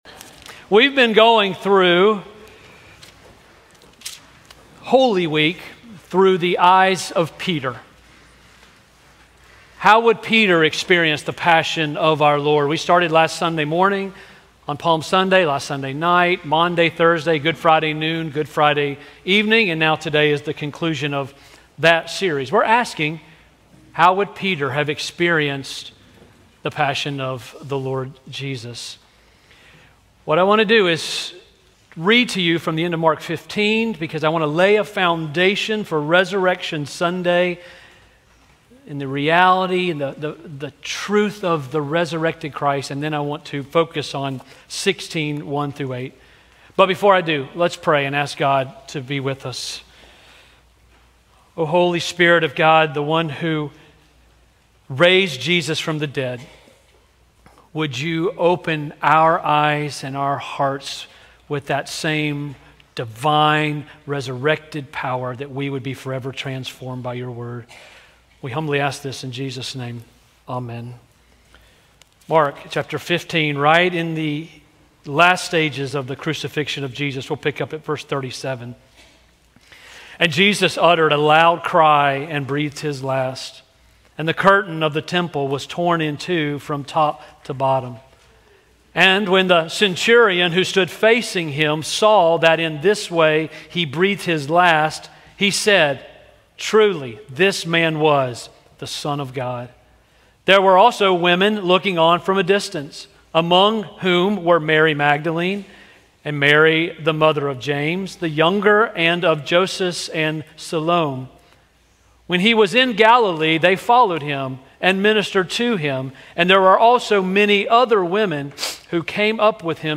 Sermons - First Presbyterian Church of Augusta